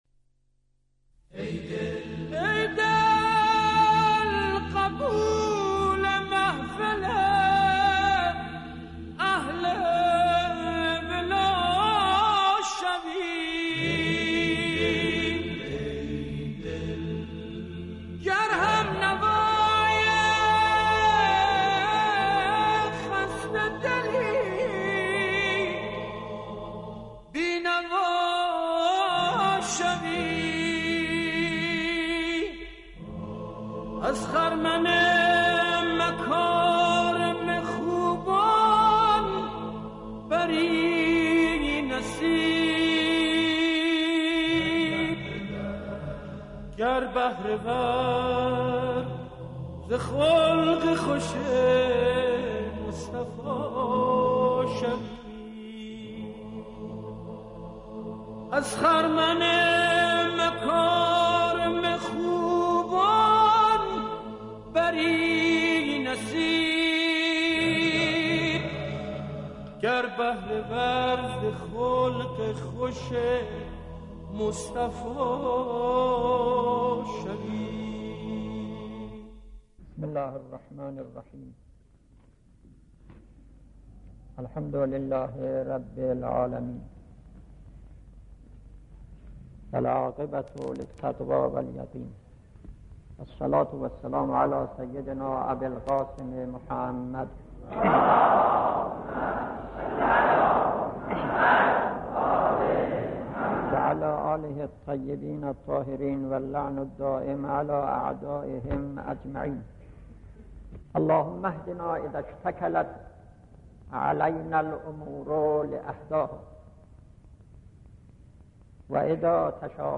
بیانات ارزشمند مرحوم آيت‌الله علی مشکینی رضوان الله علیه را در خصوص «رابطه متقابل علم و عمل» با علاقمندان به اشتراک می گذاریم.